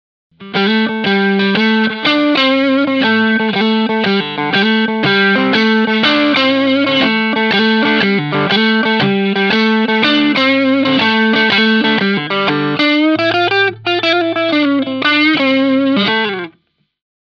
Gespielt habe ich je nach Sample eine Les Paul Style Gitarre, eine Strat oder eine Powerstrat. Als Box kam eine Marshall 4x12 mit Greenbacks zum Einsatz und abgenommen wurde mit einem SM57 direkt in den PC.
Blues